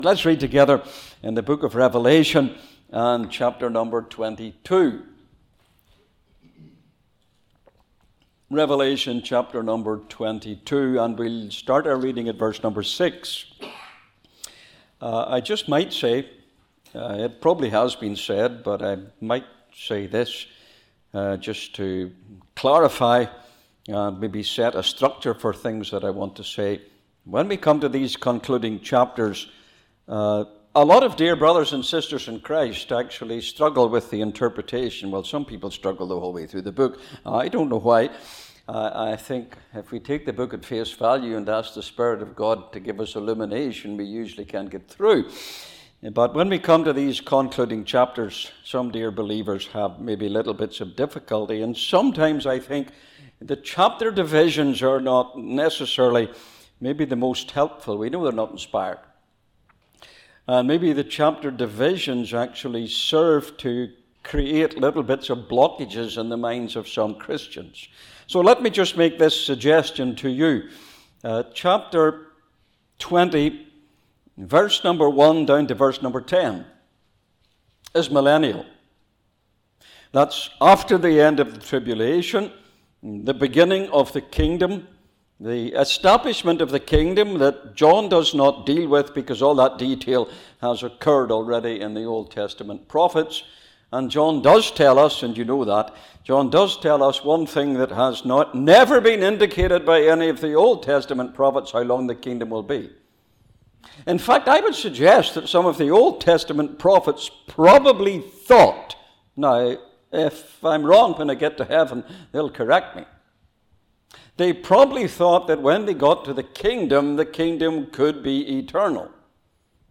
The talk concludes by encouraging believers to remain watchful, faithful, and ready, assured of God’s complete and eternal plan. Location: Cooroy Gospel Hall (Cooroy, QLD, Australia)